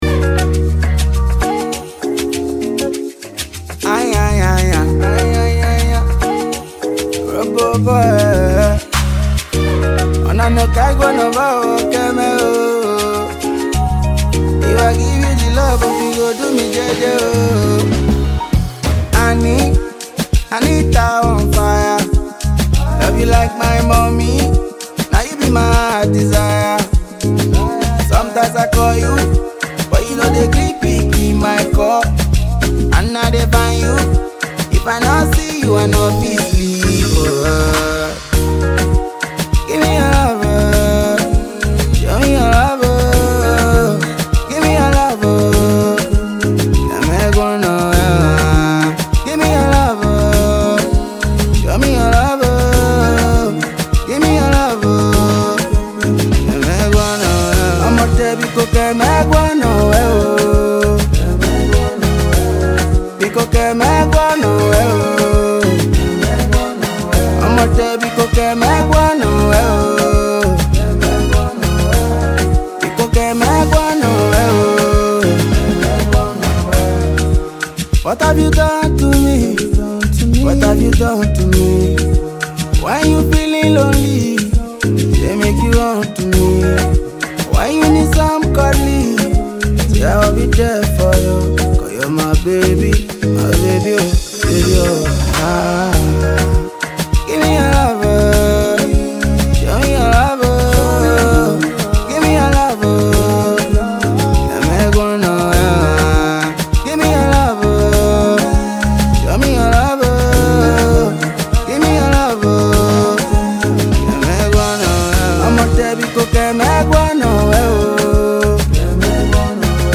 Nigerian Afro Pop/ AfroBeat artiste